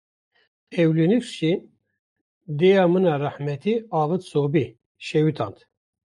Pronounced as (IPA) /leːˈnuːsk/